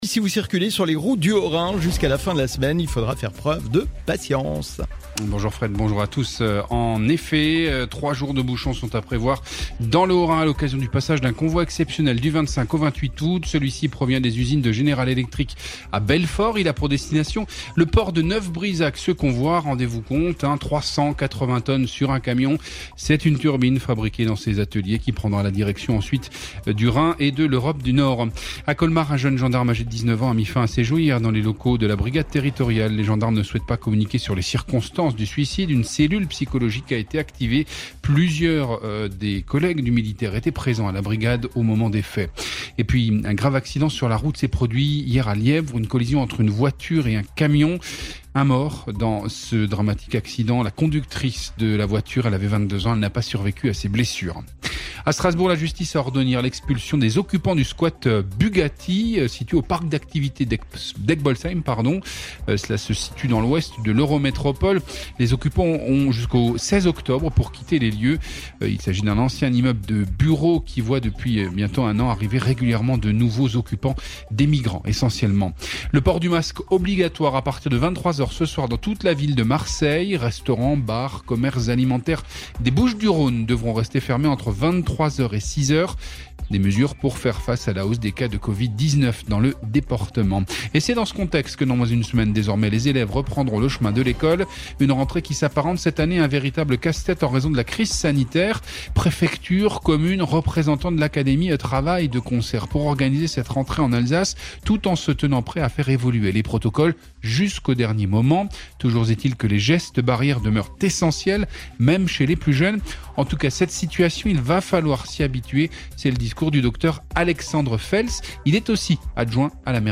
mercredi 26 août Le journal de la rédaction de DKL